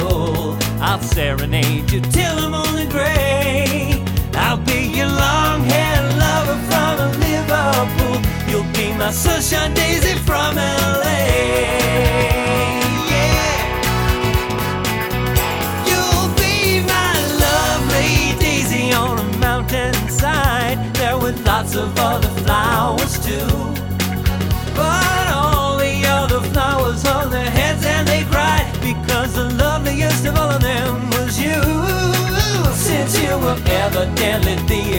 # Traditional Pop